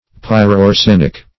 Search Result for " pyroarsenic" : The Collaborative International Dictionary of English v.0.48: Pyroarsenic \Pyr`o*ar*sen"ic\, a. [Pyro- + arsenic.]